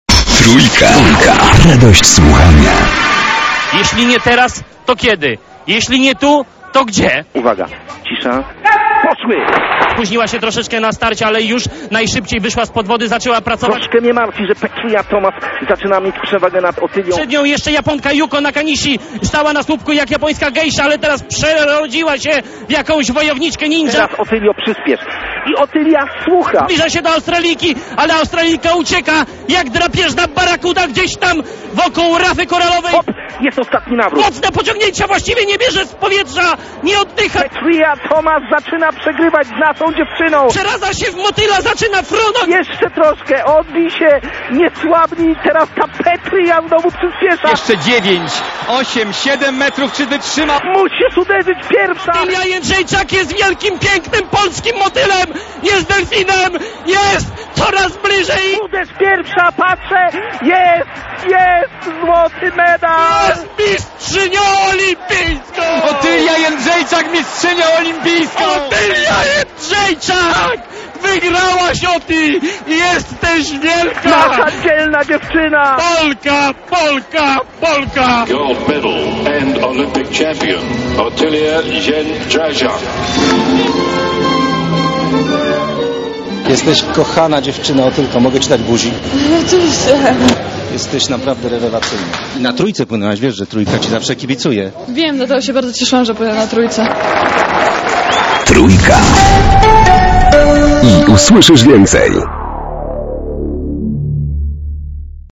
Posłuchaj pasjonującej relacji reporterów Jedynki, Trójki i TVP